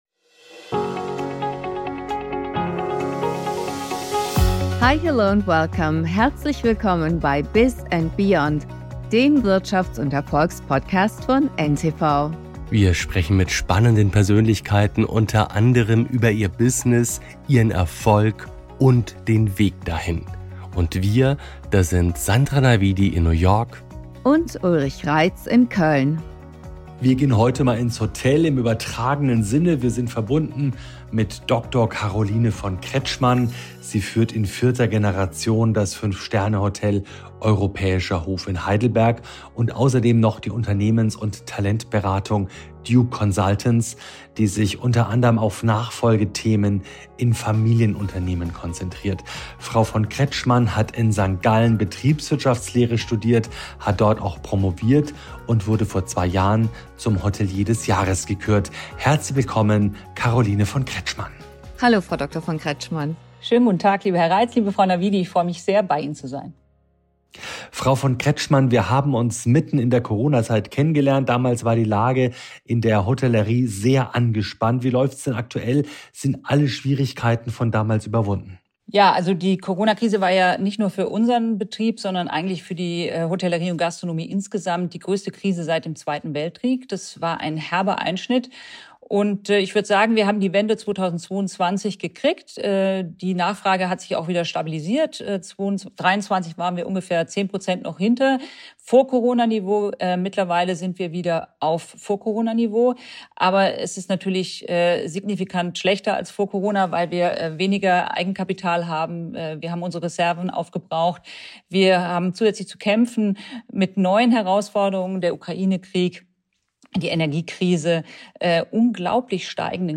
Ein spannendes Gespräch über dienende Führung, achtsames Vertrauen und klares wie kritisches Feedback.